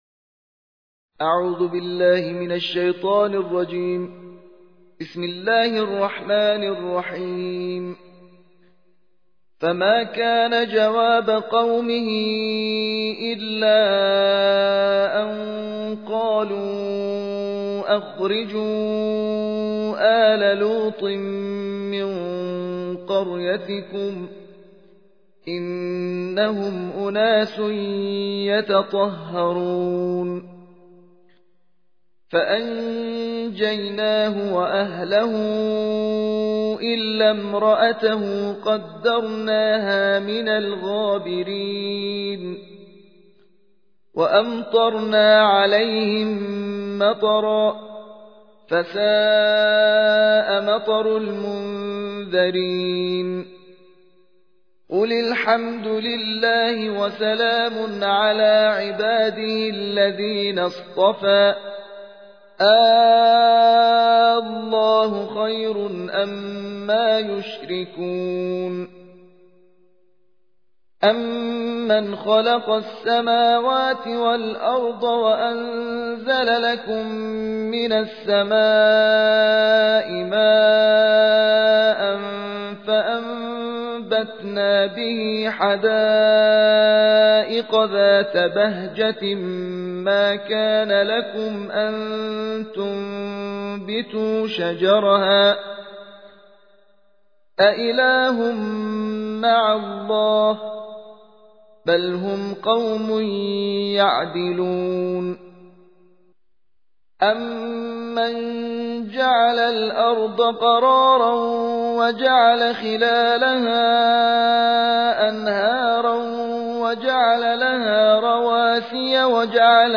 ترتیل قرآن جزء ۲۰